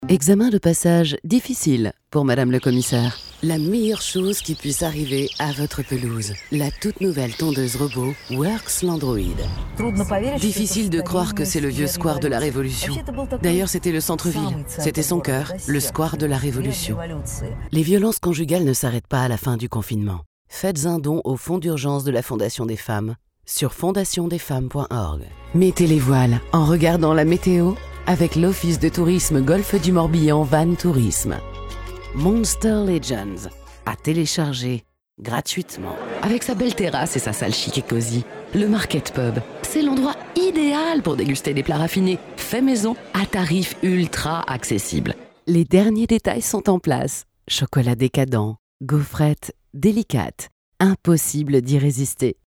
Voix off
Voix grave, chaude, sexy si nécessaire, utilisée aussi pour chanter du rock et du blues, plus douce à la demande, et de nombreuses autres variantes disponibles.
Deep voice, warm, sexy if required, used to sing rock jazz and blues, smoother on demand, and many others variations available.
22 - 65 ans